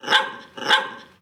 Ladridos de un perro pequeño 02